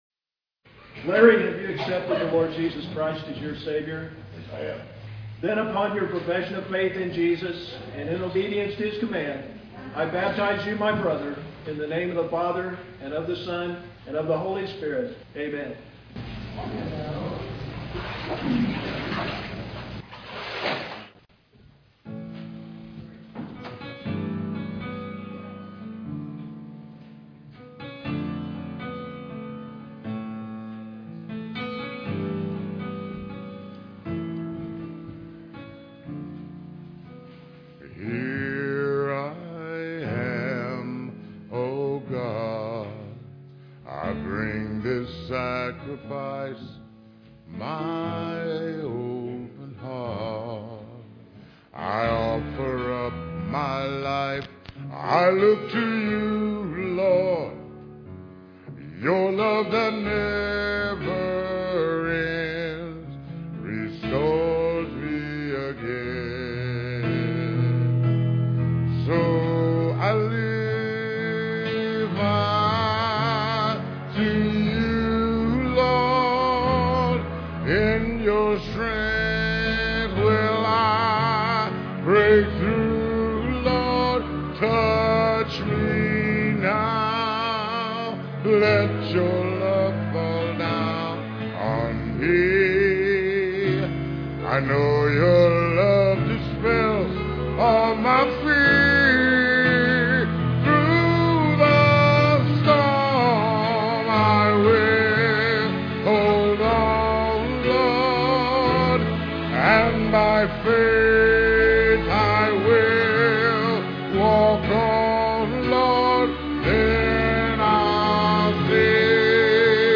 Piano and organ offertory